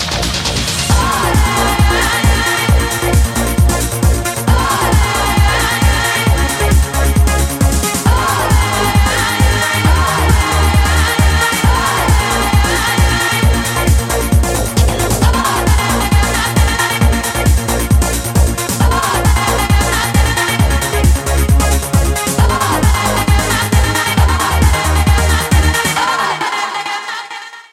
• Качество: 128, Stereo
электроника